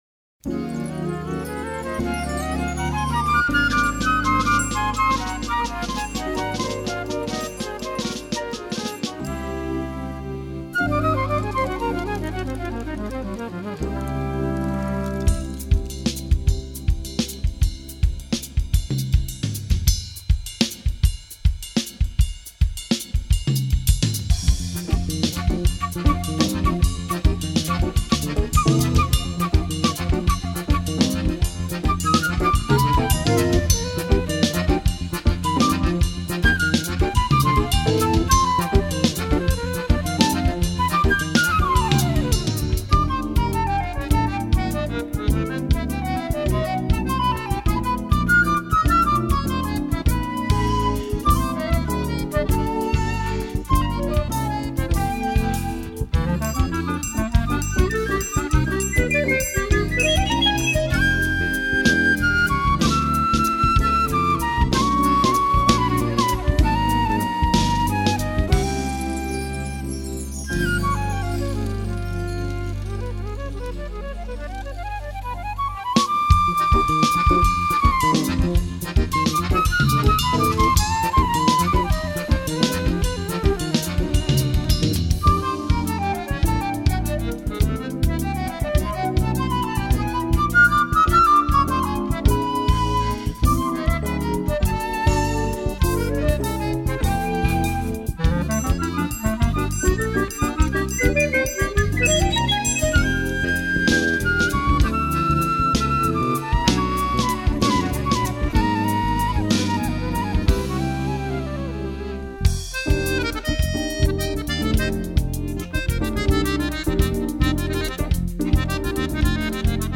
946   05:20:00   Faixa:     Jazz